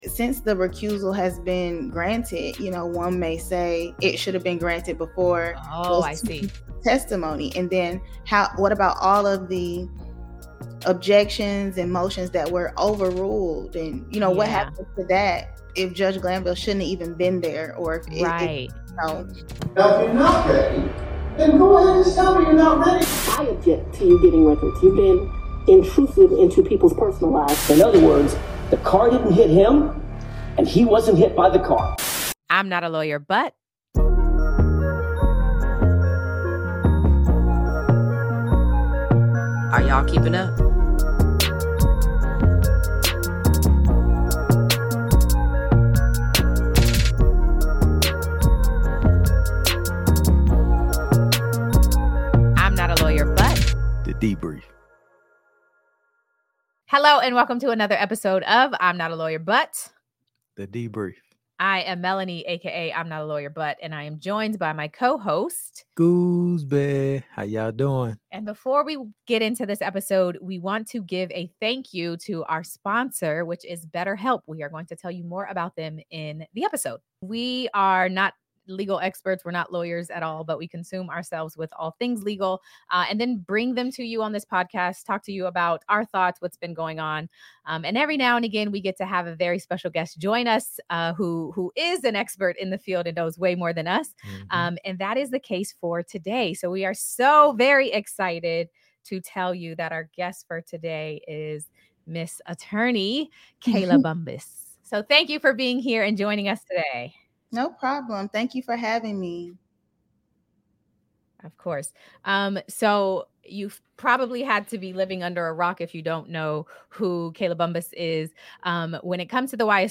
I'm Not A Lawyer But: The Debrief / The Debrief: Judge #3 in the YSL RICO Trial?! Interview